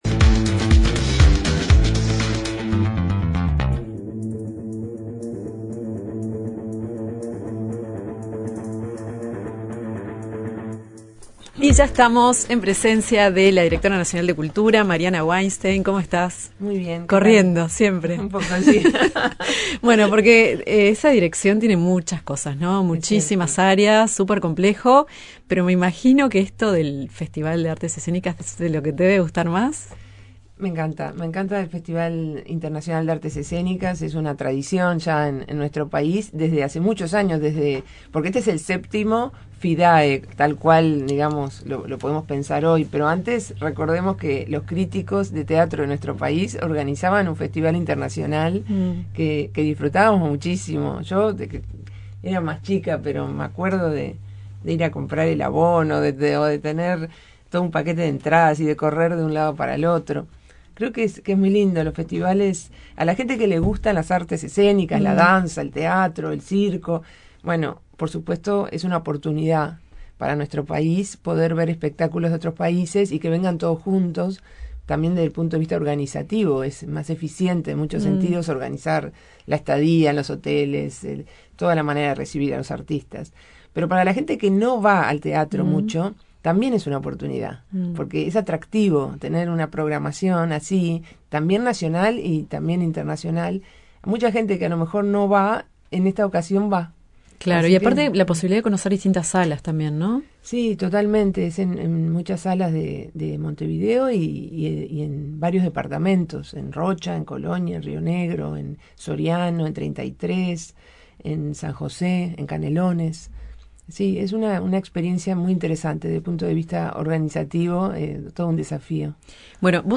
Conversamos con la directora nacional de Cultura, Mariana Wainstein